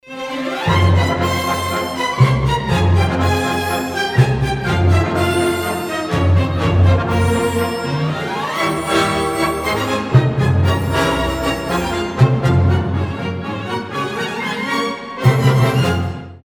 • Качество: 320, Stereo
веселые
без слов
оркестр
эпичные